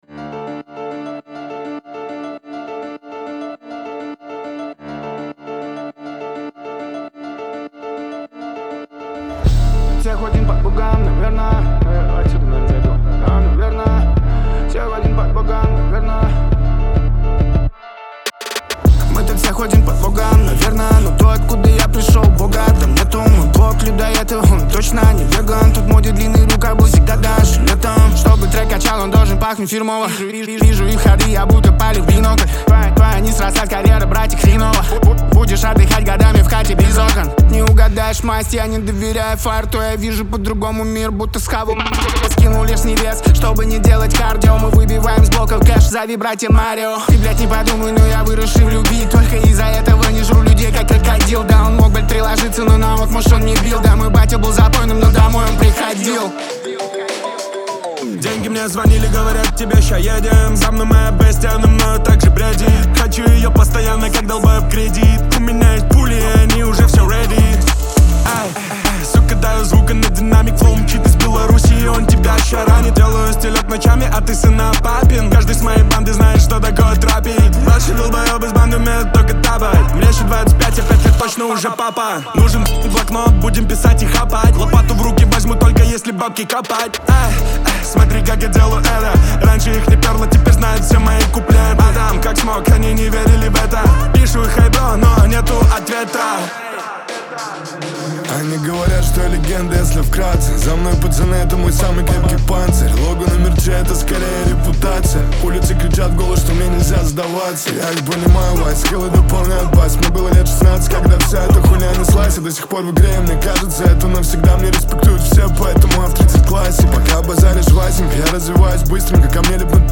Трек создает мрачную, напряженную и агрессивную атмосферу.